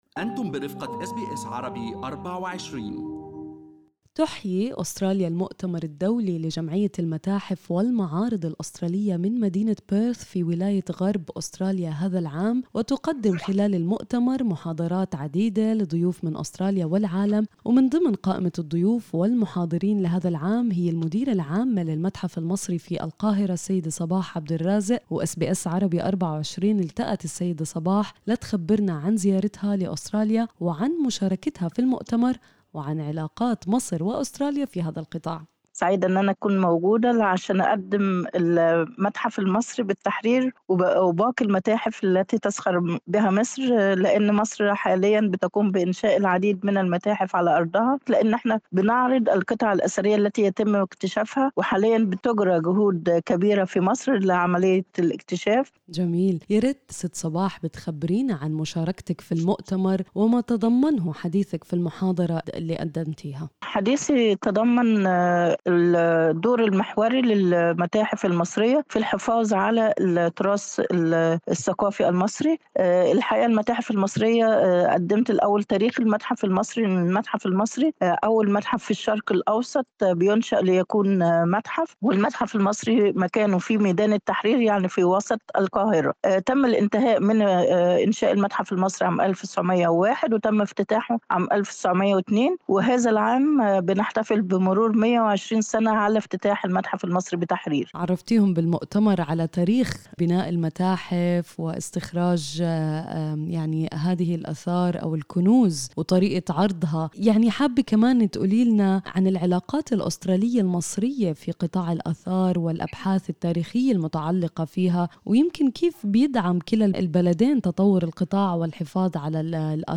أس بي أس عربي24 أجرت معها لقاء حول أهداف المؤتمر والتعاون بين مصر وأستراليا في مجال الآثار.